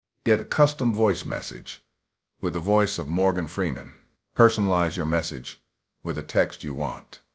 Custom Voice Message with Morgan Freeman’s Voice – Official Shop
Get a custom voice message with the voice of Morgan Freeman.
The audio message is generated by artificial intelligence and should not be used to create fake news and commit crimes.